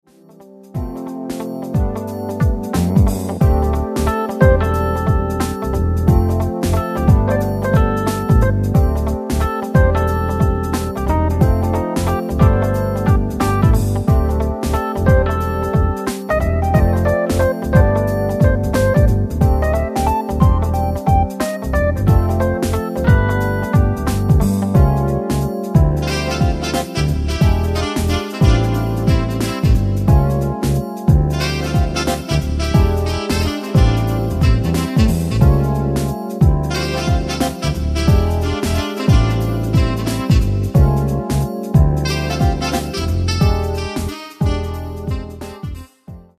Un clin d'oeil au "smooth-jazz" dépeignant la joie de vivre.